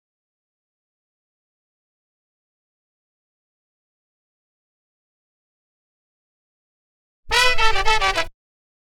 DF_107_E_FUNK_HORNS_02.wav